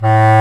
Index of /90_sSampleCDs/Roland L-CDX-03 Disk 1/WND_Lo Clarinets/WND_CB Clarinet